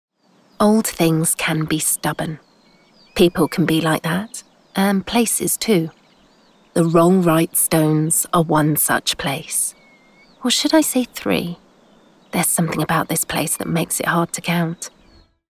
Acting Voice Over Artists | Voice Fairy